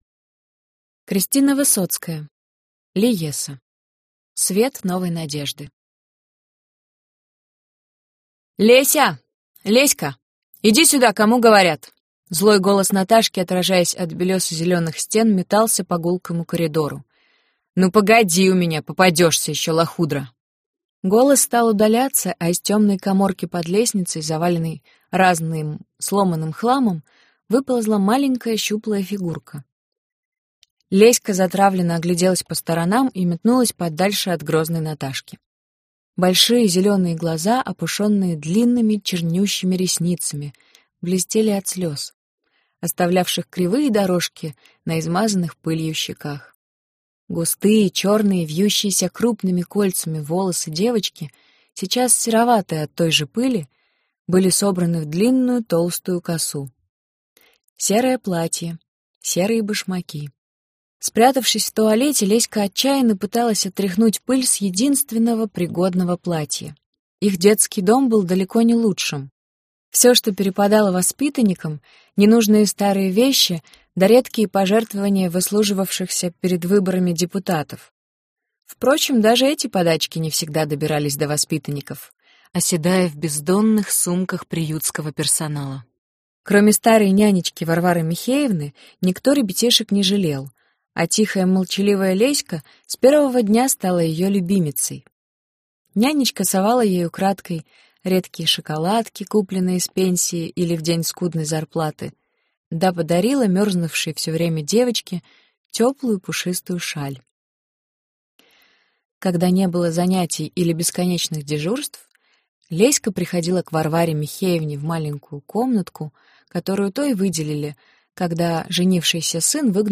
Аудиокнига Лиесса. Свет новой надежды | Библиотека аудиокниг